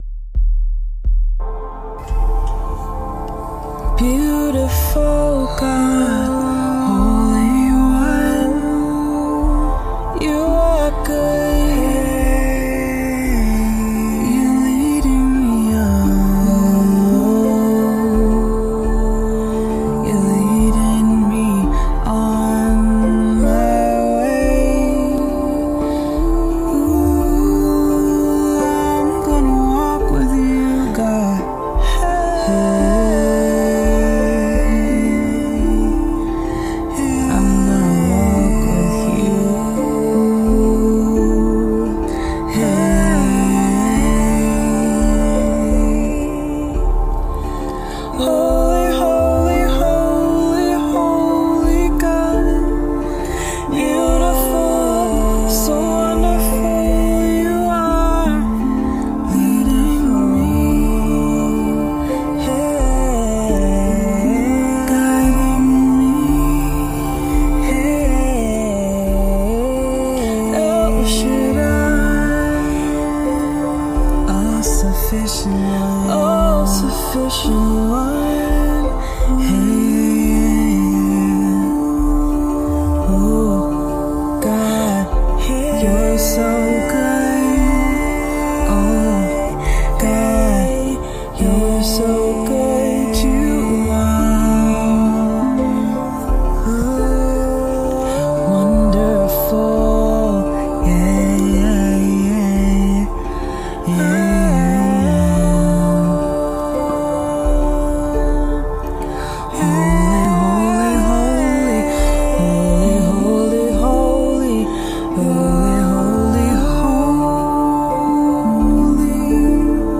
improvised worship
Rav vast drum
spontaneous worship # tongue drum